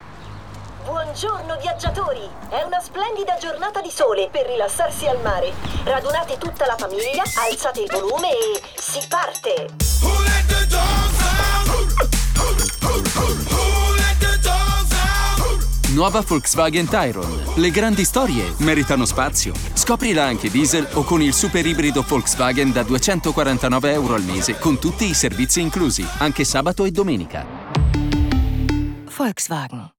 Spot TV
-Locale chiuso fornito di pannelli fonoassorbenti